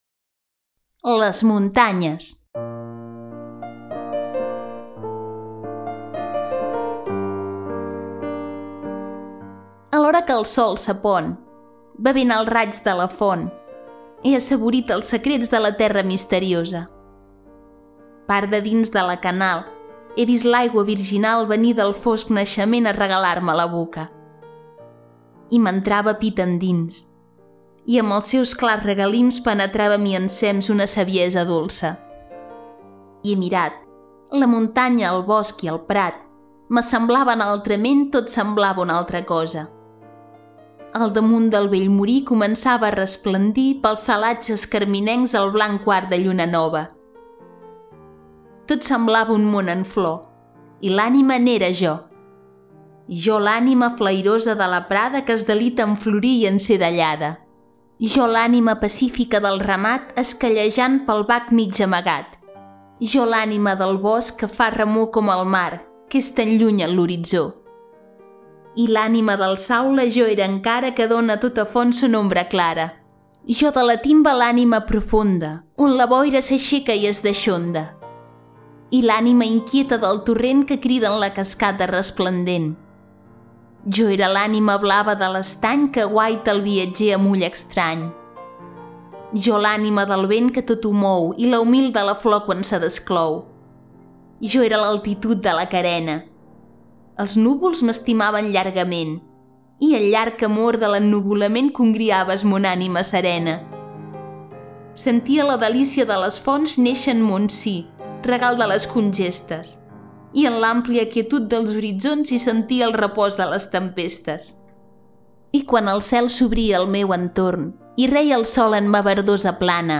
Audiollibre: Poemas (Maragall)